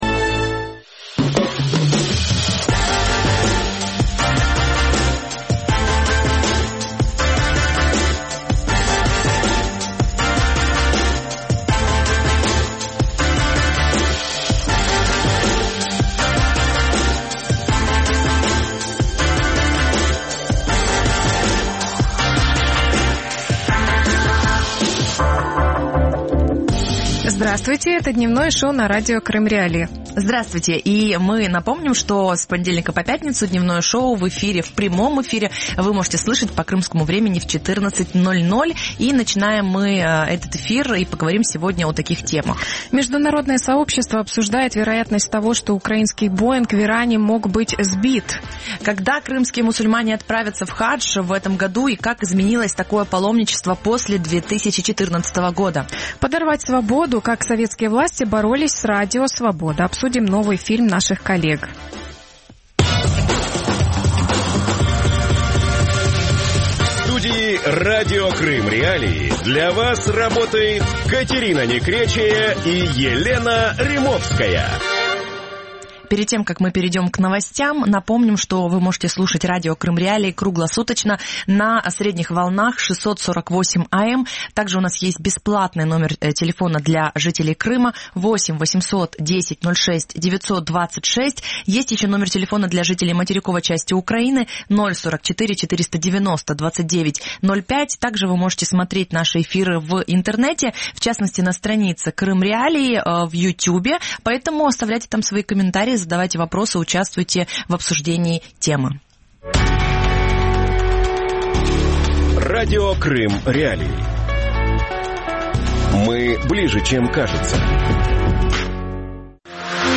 В Мекку из Крыма | Дневное ток-шоу